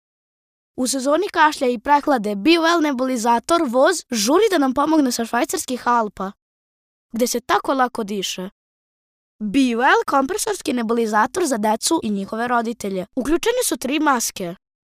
Serbian child voice over